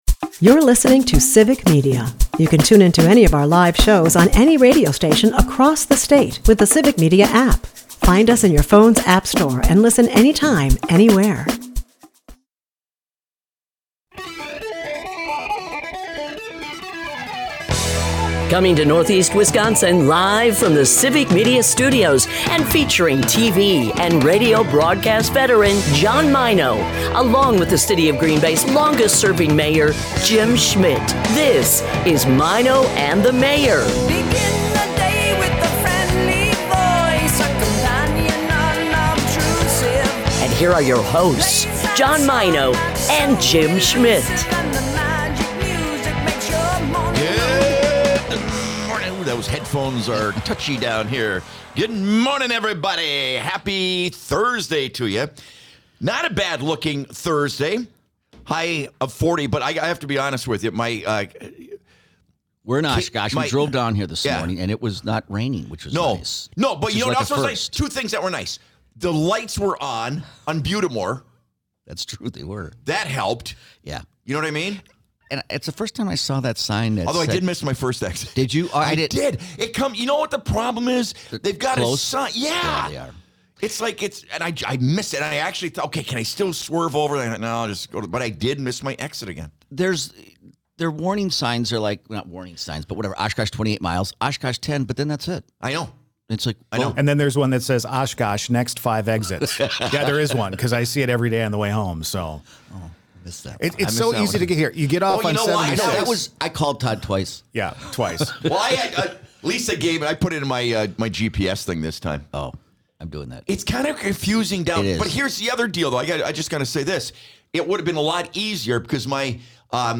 It's another show from Oshkosh. If you're wondering, the guys showed up on time but still had problems finding the studios.